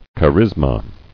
[cha·ris·ma]